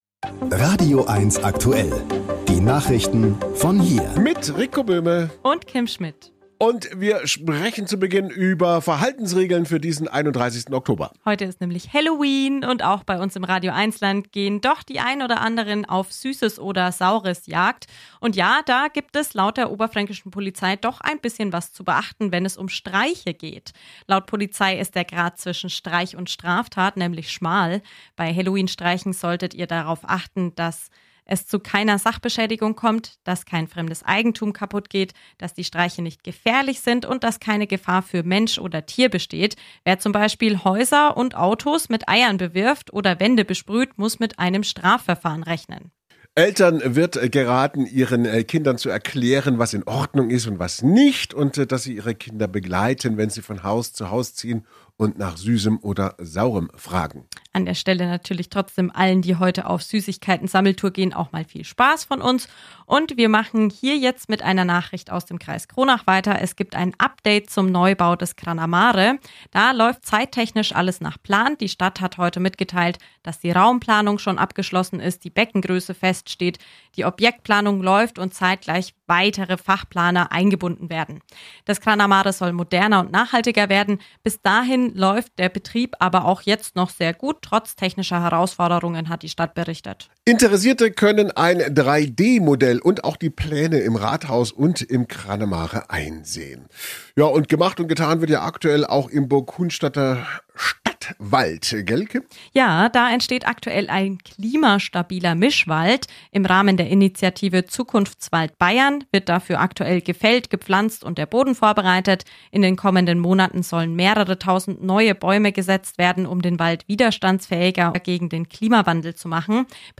Die RadioEINS-Lokalnachrichten vom 31.10. - Spätausgabe